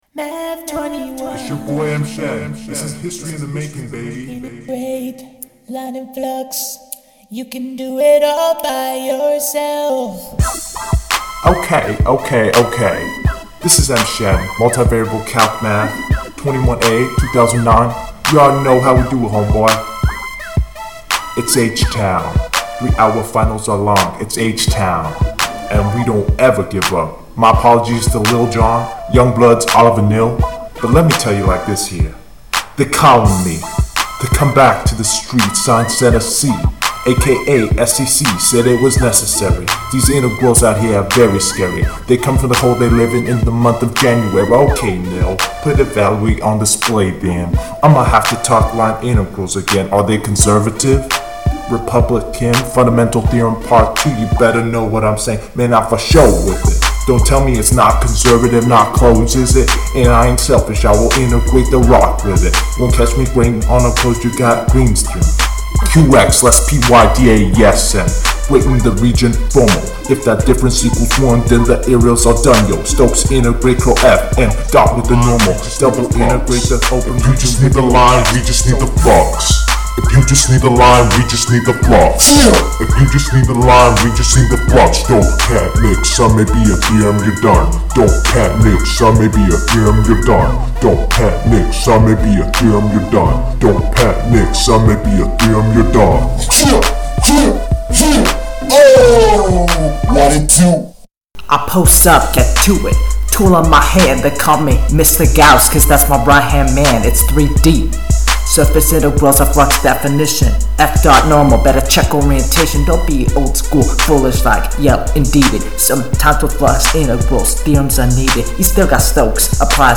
a multivariable Rap performance